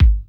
Kick_62.wav